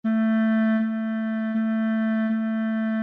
Piano (Corda percutida) | Orquestra de cARTón (ODE5)
LA.mp3